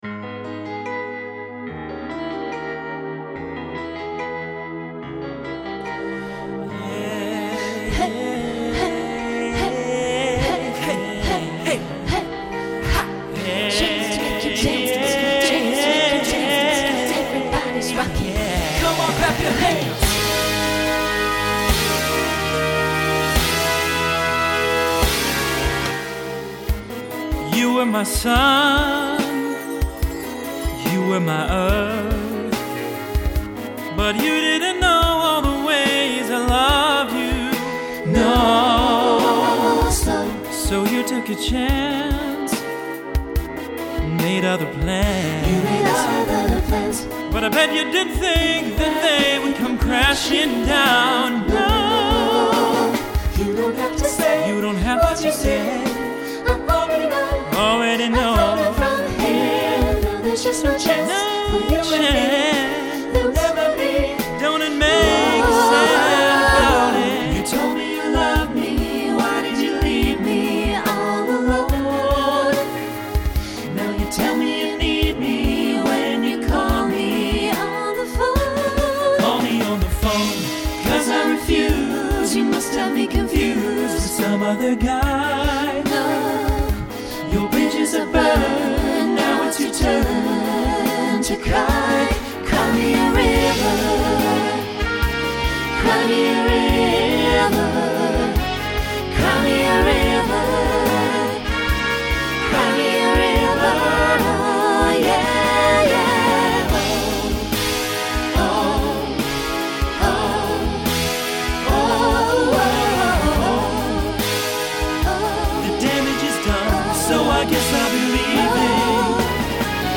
SATB Instrumental combo
Pop/Dance
Mid-tempo